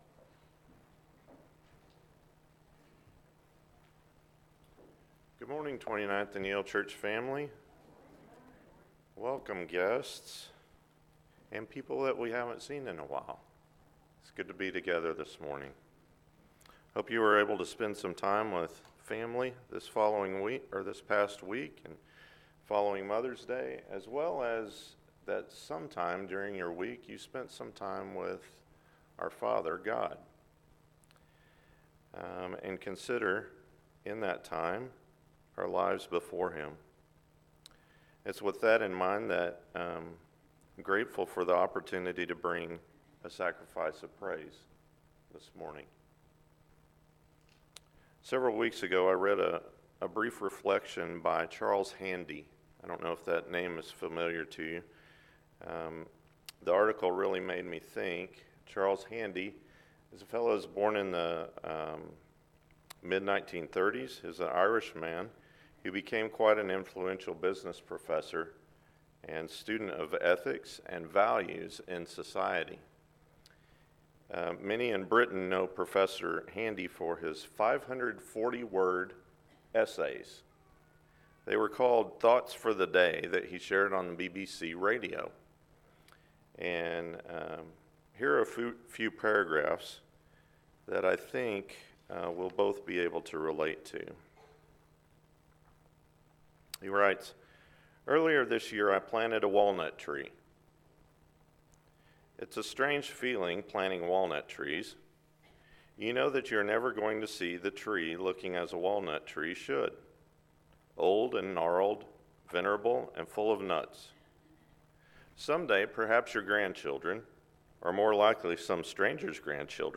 More to the Story: Samuel – Sermon